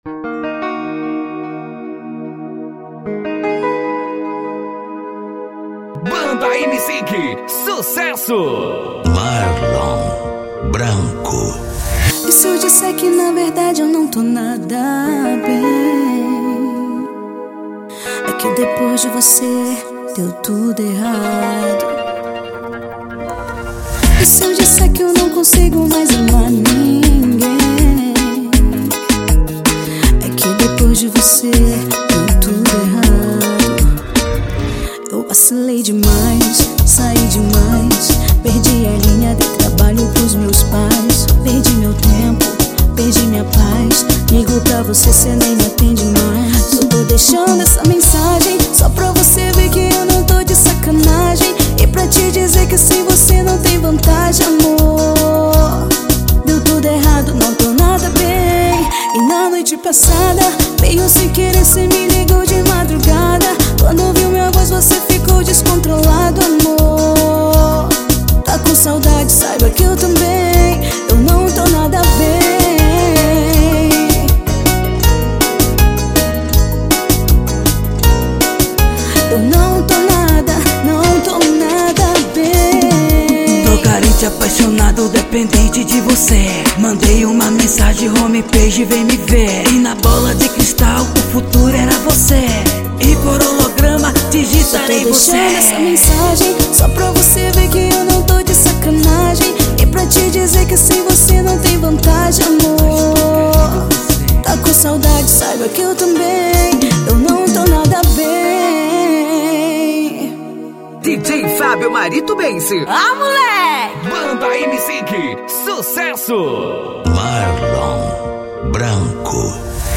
OUÇA NO YOUTUBE Labels: Melody Facebook Twitter